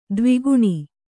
♪ dvi guṇi